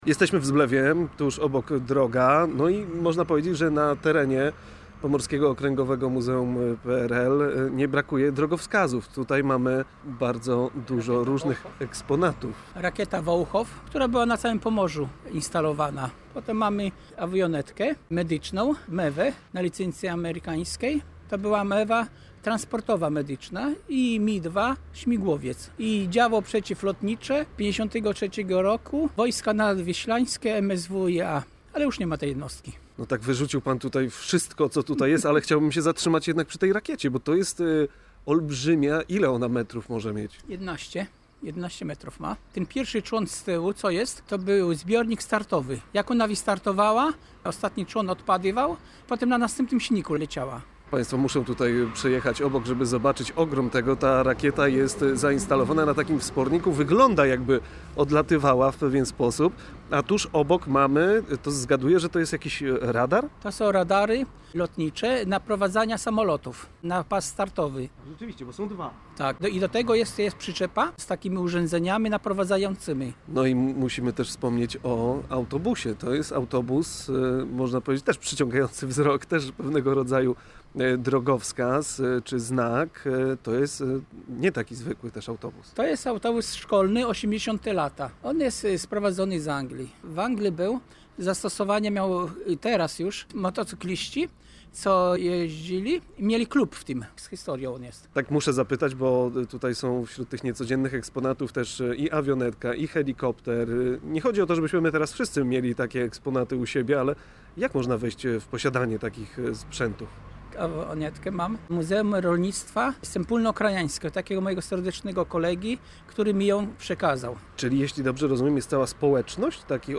W audycji odwiedziliśmy Pomorskie Okręgowe Muzeum PRL w Zblewie, które powstało w 2022 roku.